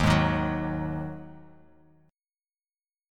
D#mbb5 chord